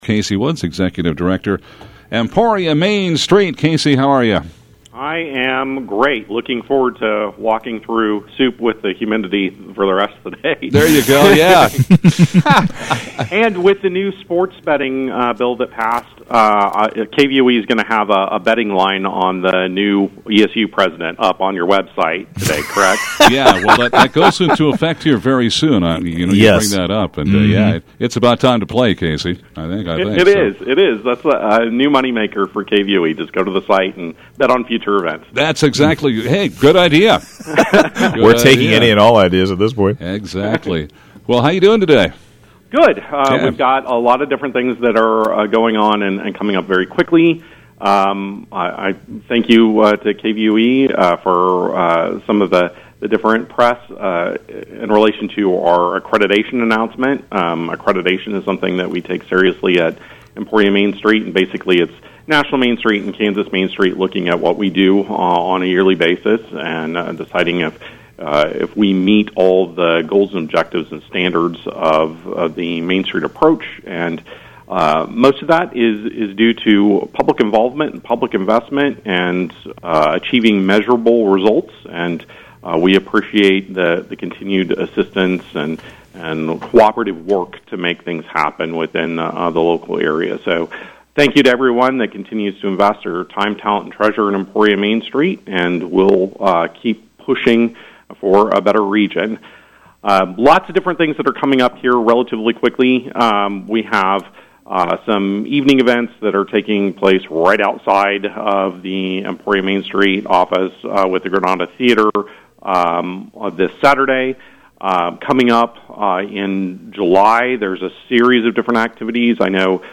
Live Call-In: Emporia Main Street